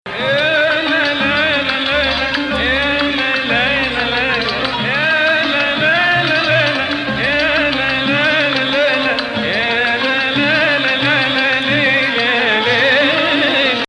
Rast 6